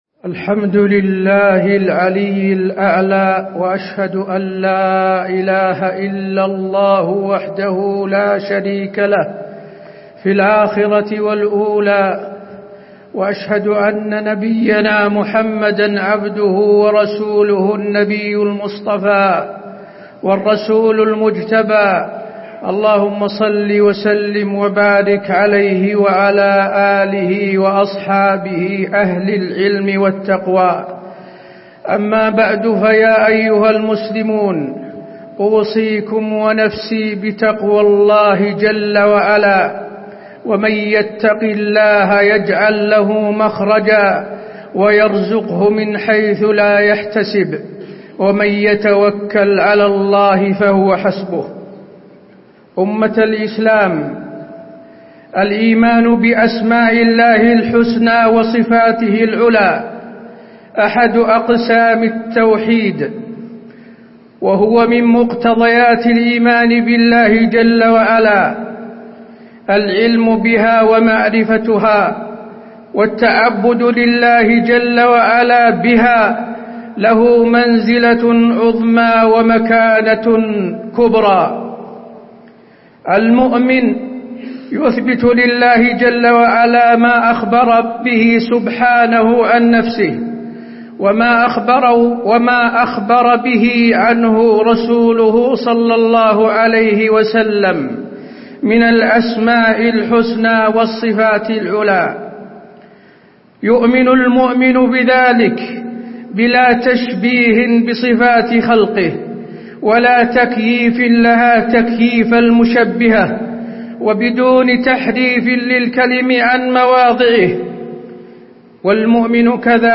تاريخ النشر ٢٥ شعبان ١٤٣٩ هـ المكان: المسجد النبوي الشيخ: فضيلة الشيخ د. حسين بن عبدالعزيز آل الشيخ فضيلة الشيخ د. حسين بن عبدالعزيز آل الشيخ التعبد لله تعالى بأسمائه وصفاته